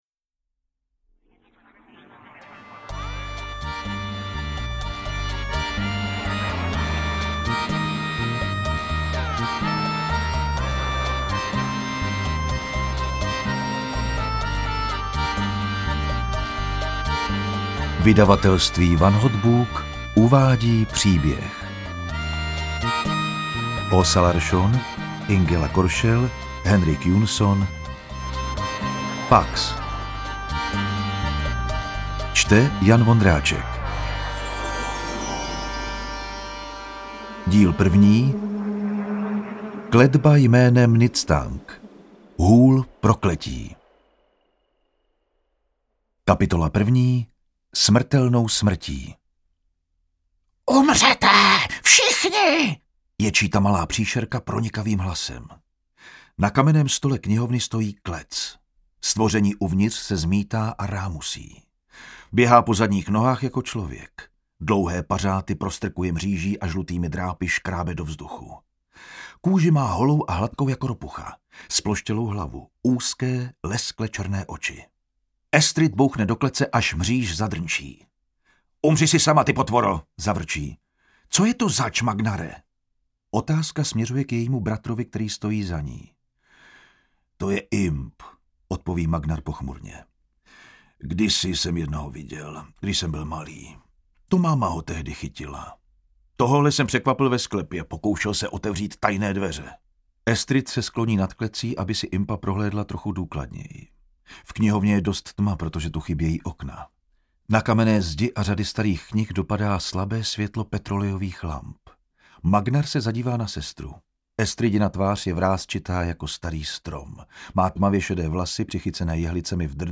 Interpret:  Jan Vondráček